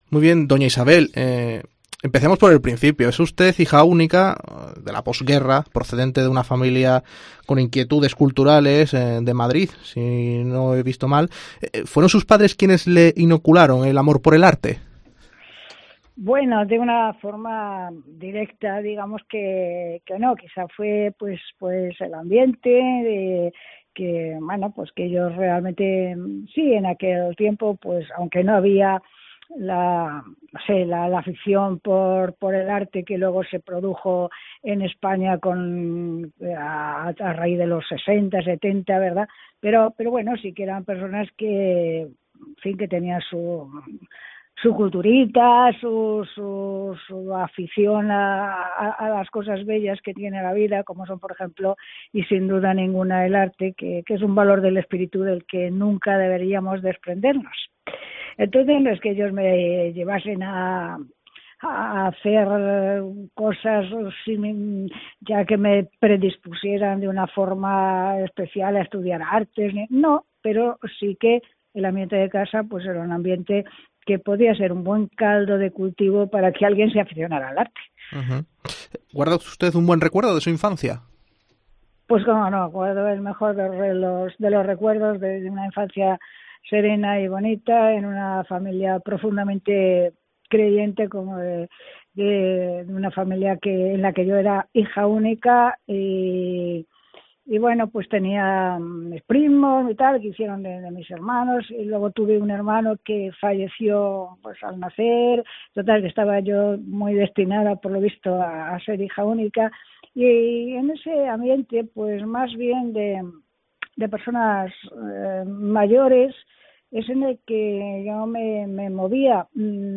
En una entrevista concedida a Aleluya, la conocida como 'monja pintora' ha repasado su prestigiosa trayectoria y advierte que el arte está más...